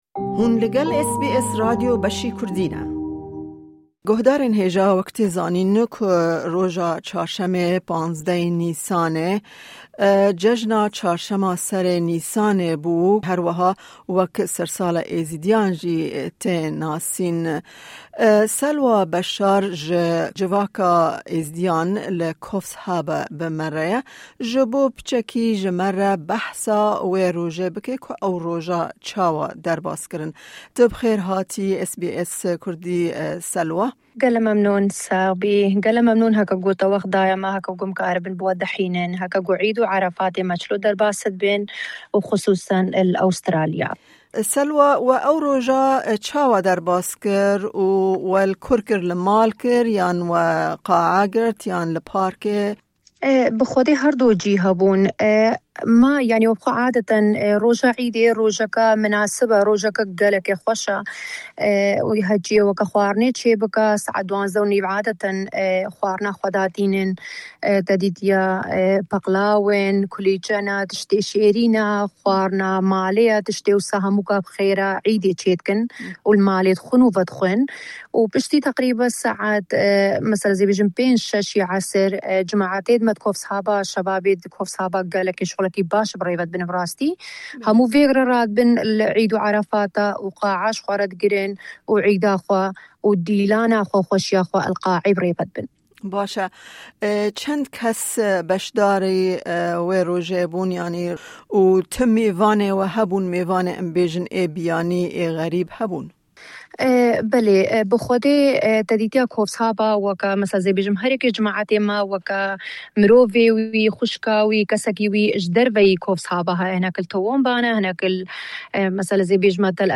She explains that it is a joyful and meaningful celebration. Families prepare special food at home, including sweets like pastries and traditional dishes, and they gather to eat together around midday.